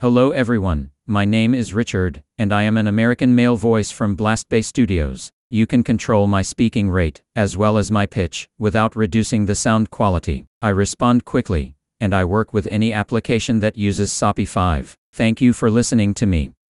[DECtalk] Formant-based Neural Text to Speech Voices from Blastbay Studios
Here's what it says Richard (English United States)
blastbay_us_richard.wav